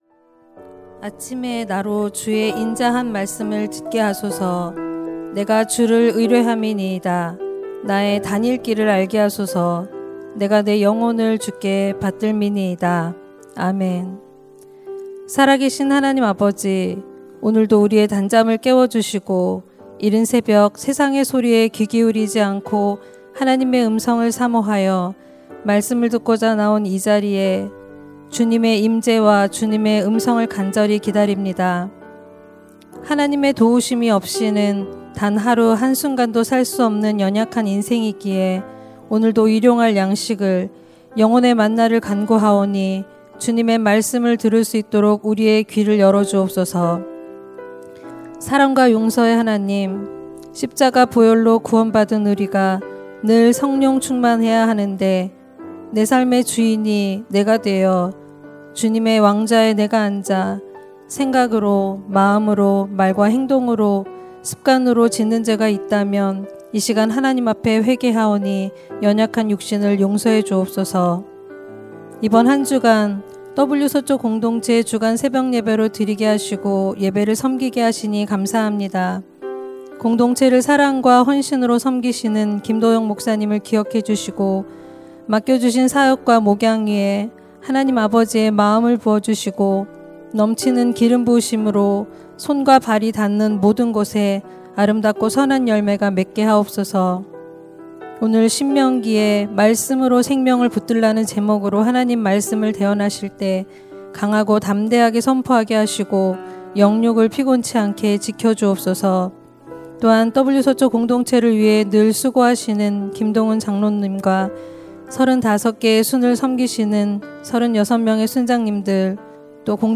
> 설교
[새벽예배] 2026-03-24 공동체 주관 새벽기도회